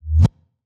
ForceGrab.wav